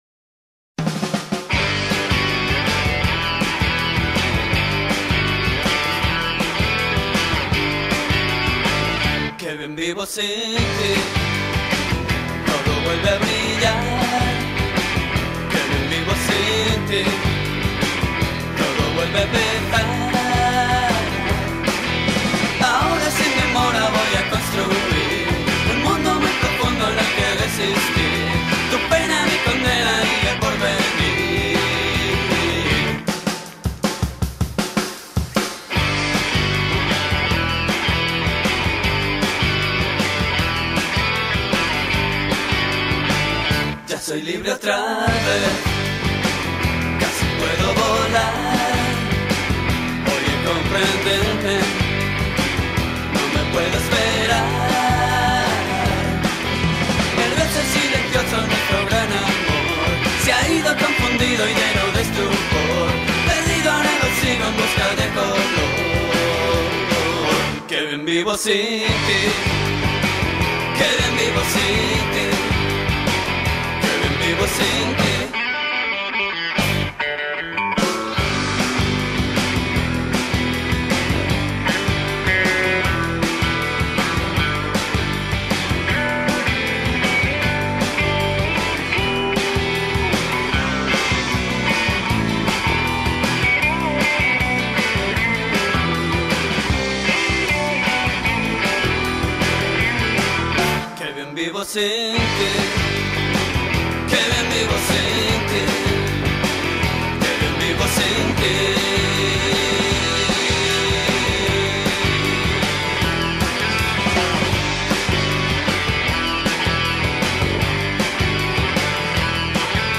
a collection of covers